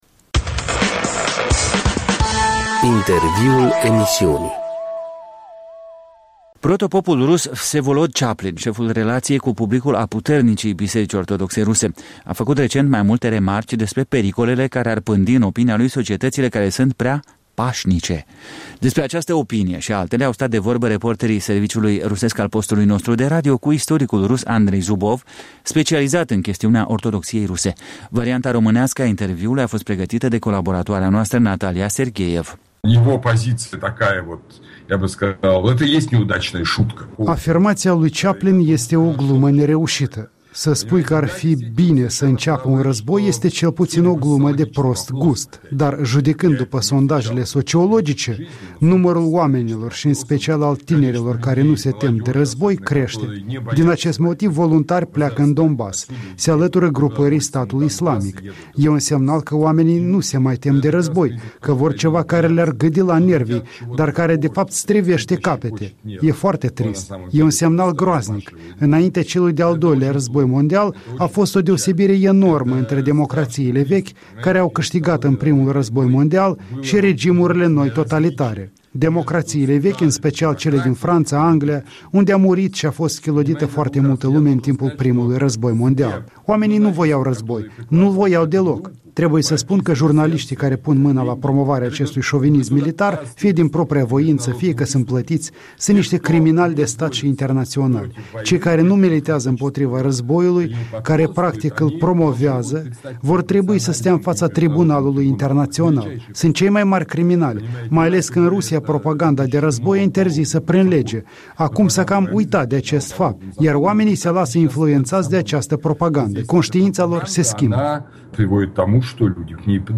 Interviu cu istoricul rus Andrei Zubov